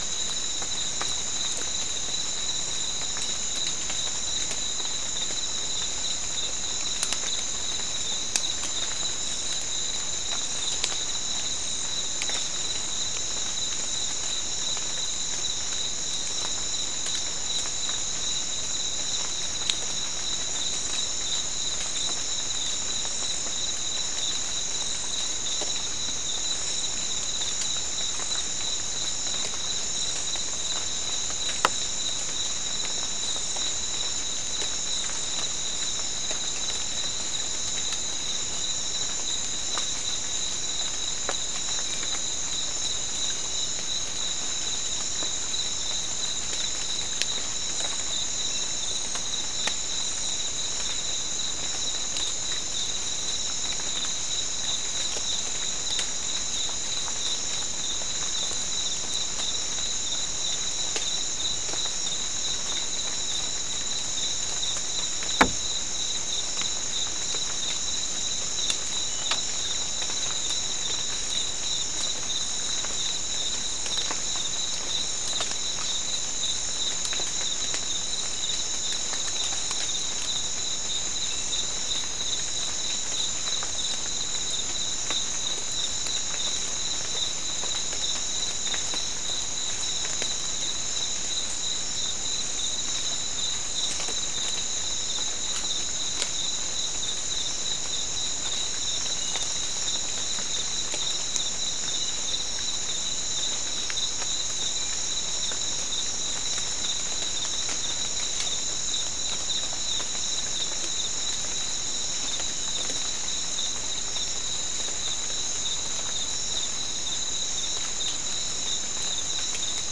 Soundscape Recording Location: South America: Guyana: Sandstone: 2
Recorder: SM3